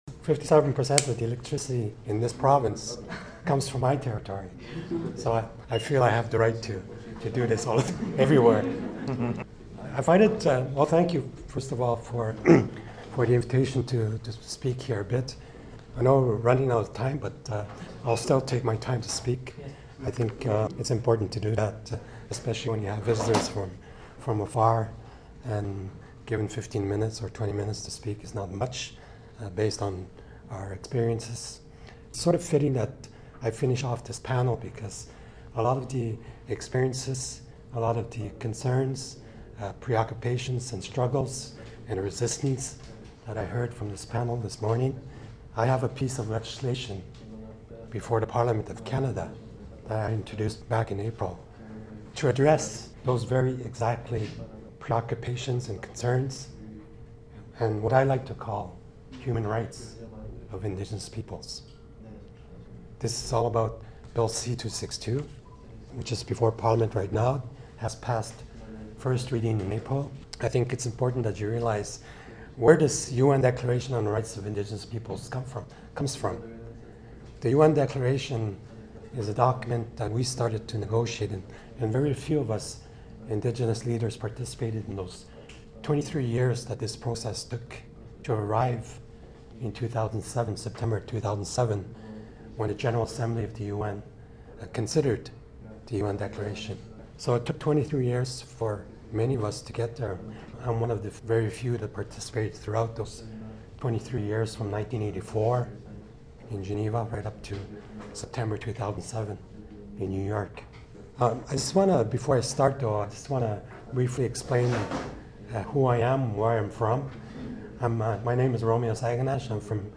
romeo-saganash-mp-w-speed.mp3